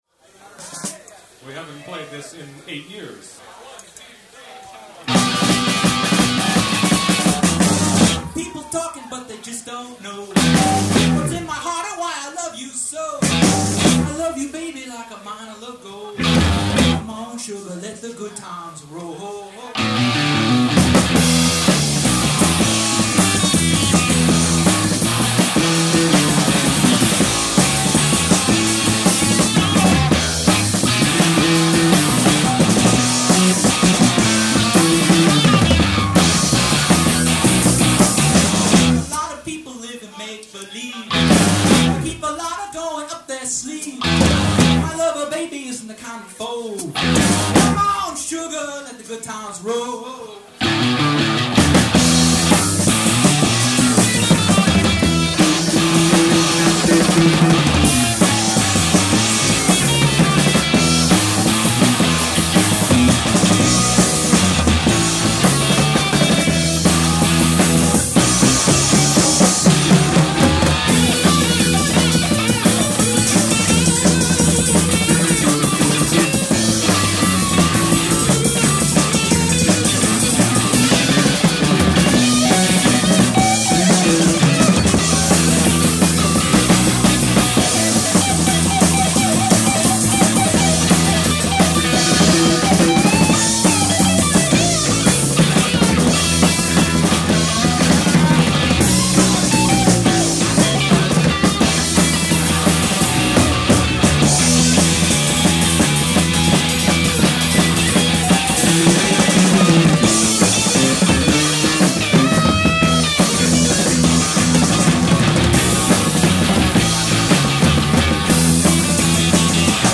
bass
drums
guitar, lead vocal